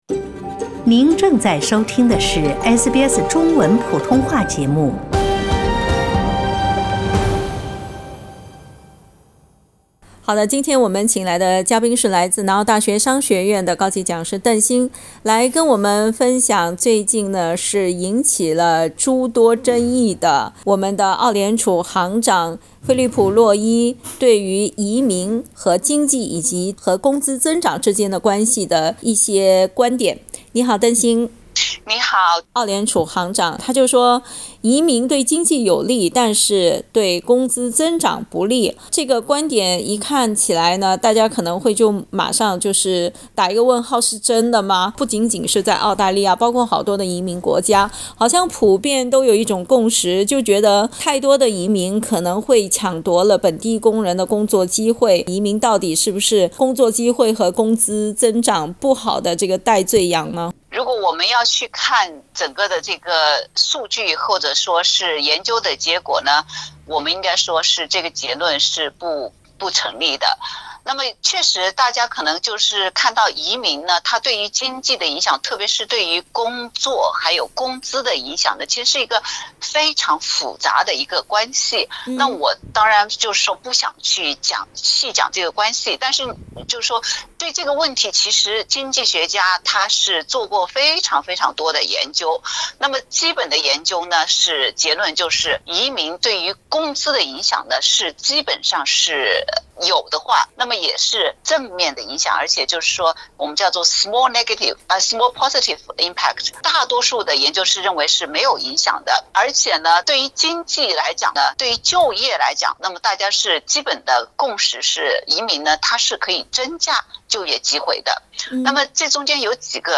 （本节目为嘉宾观点，不代表本台立场，请听采访） 澳大利亚人必须与他人保持至少1.5米的社交距离，请查看您所在州或领地的最新社交限制措施。